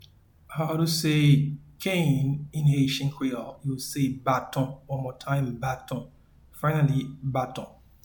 Pronunciation and Transcript:
Cane-in-Haitian-Creole-Baton.mp3